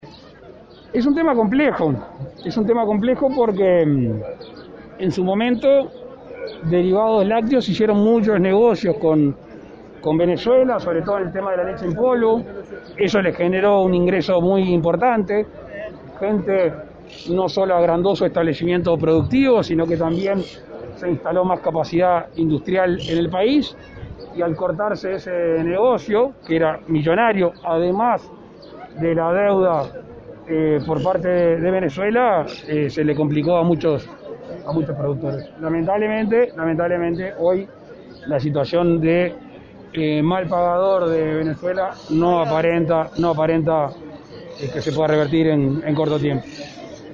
En rueda de prensa Lacalle dijo que se entiende que la pandemia puede haber retrasado alguna medida pero no puede servir de excusa”.